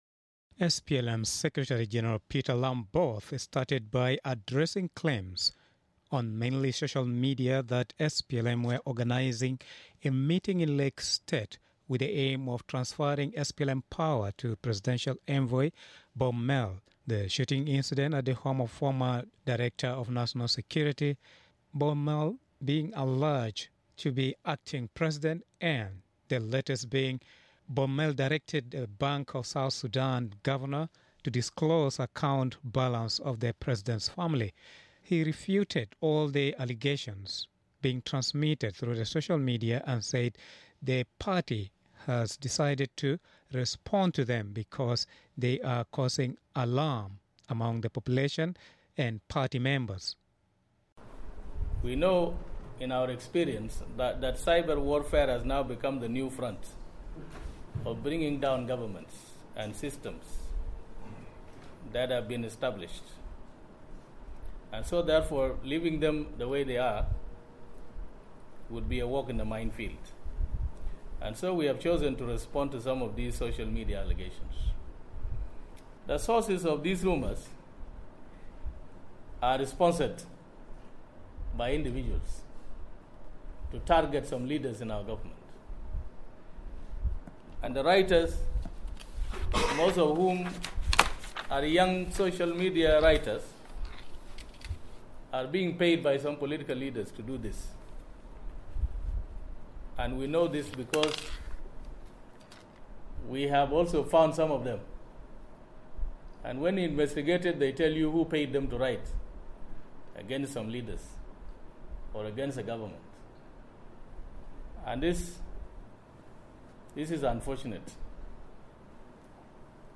News Report: SPLM decries misinformation towards government